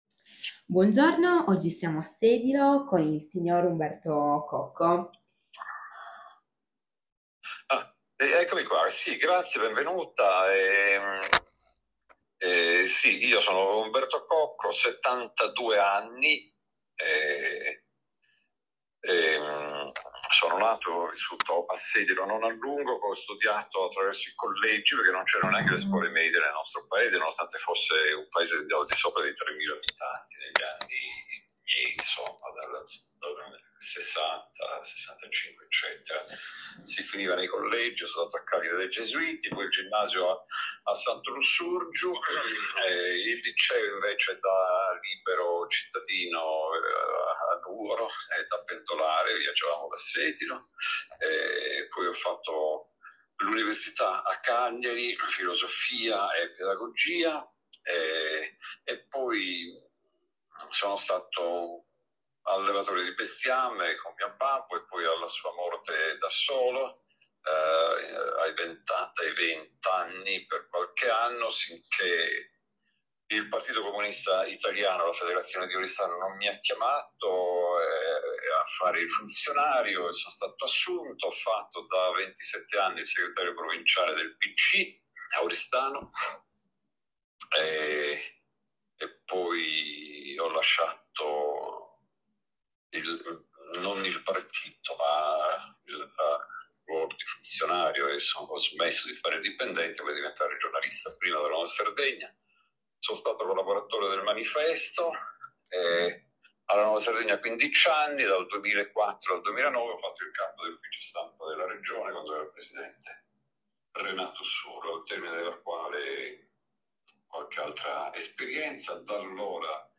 Luogo dell'intervista Sedilo
Apparecchiatura di registrazione Microfono e cellulare